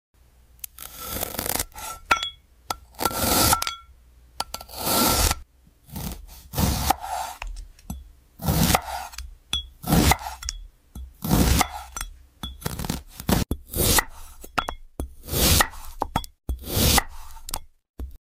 I’m not a fan of asmr w AI, but this is good 😌🎧